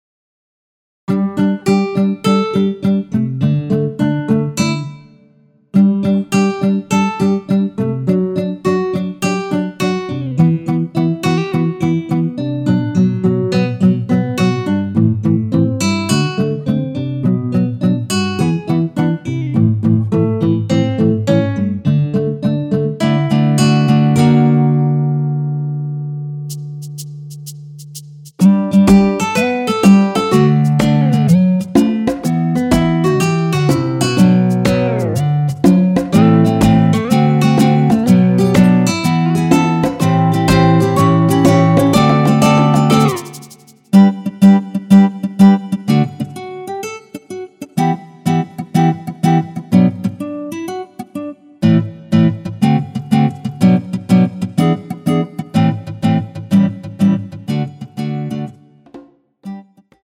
전주 없이 시작 하는곡이라 노래 하시기 편하게 전주 2마디 많들어 놓았습니다.(미리듣기 확인)
원키에서(+3)올린 MR입니다.
앞부분30초, 뒷부분30초씩 편집해서 올려 드리고 있습니다.
중간에 음이 끈어지고 다시 나오는 이유는